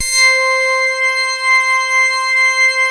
BAND PASS .8.wav